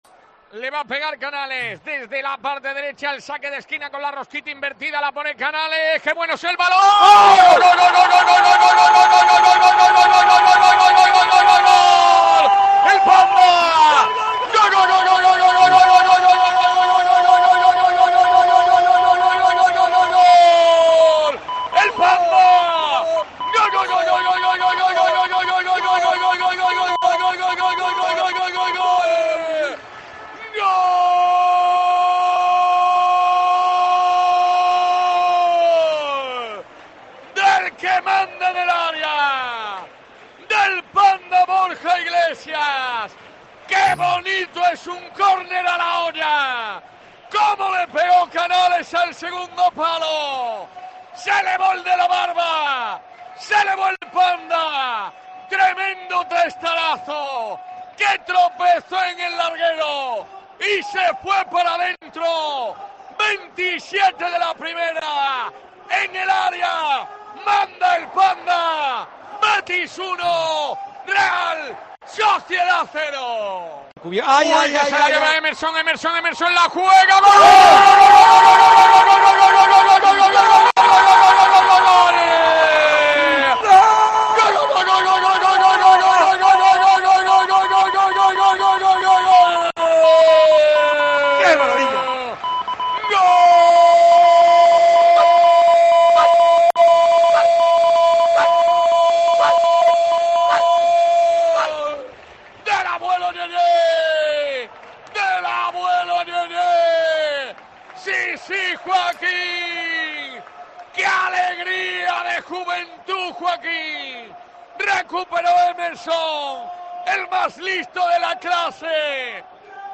Así sonaron los goles del Betis-Real Sociedad en Cope Más Sevilla 105.8FM